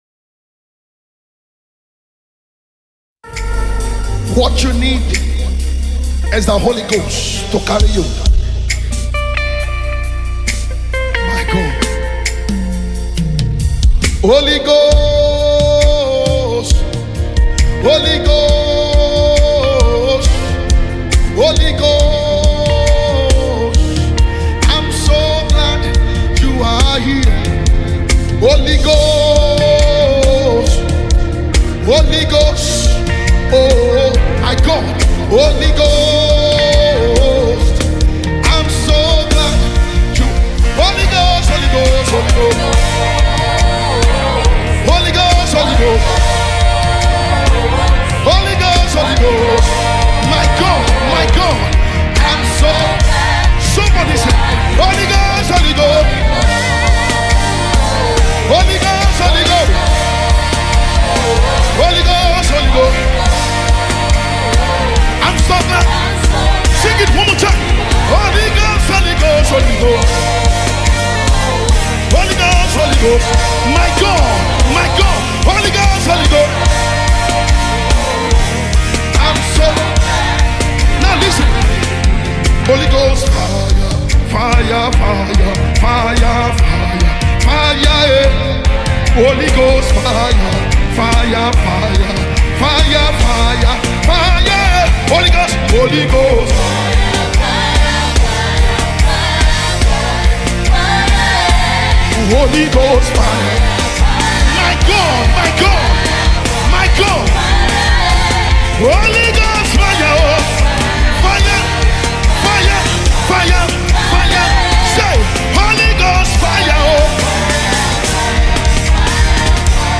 Recorded Live in the United States.